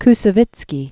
Kous·se·vitz·ky